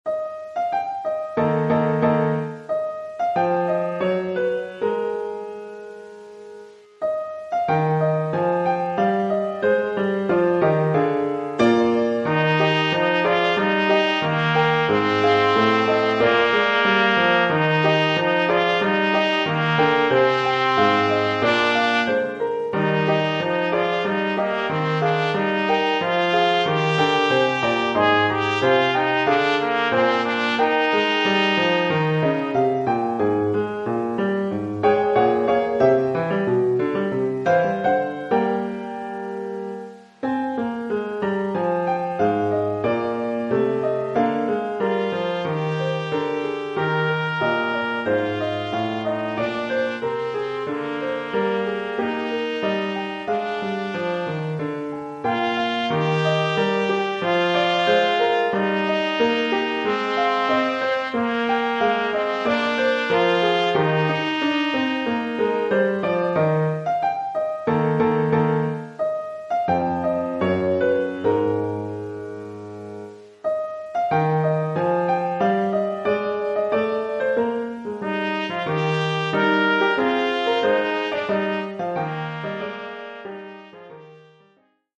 Formule instrumentale : Trompette et piano
Oeuvre pour trompette ou cornet
Niveau : débutant.